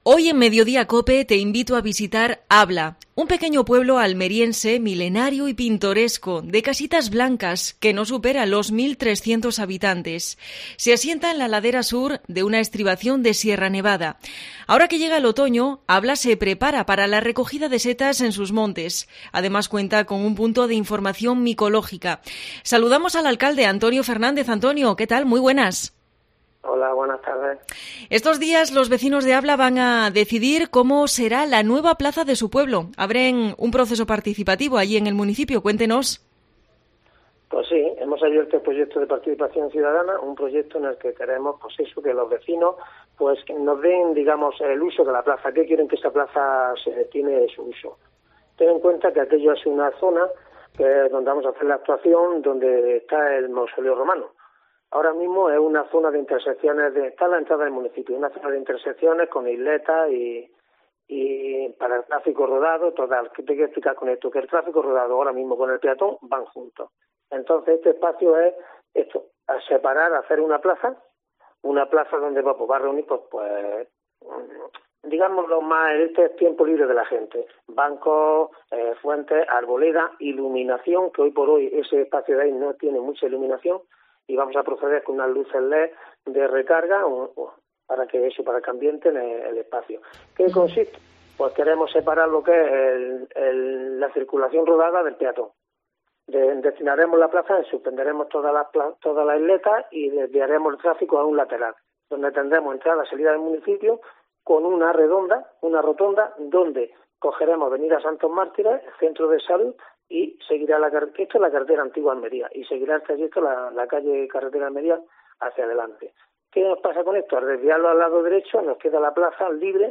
Entrevista a Antonio Fernández, alcalde de Abla